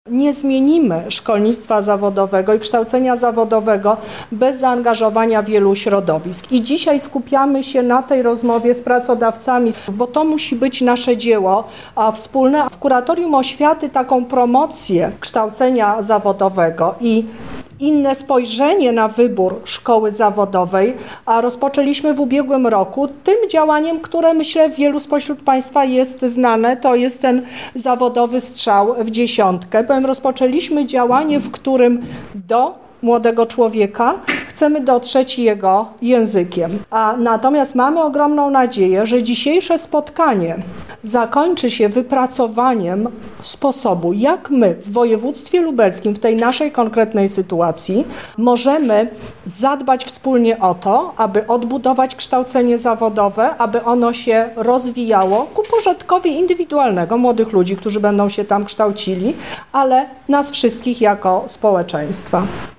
O promowaniu tego kształcenia wśród uczniów i ich rodziców mówi Lubelski Kurator Oświaty Teresa Misiuk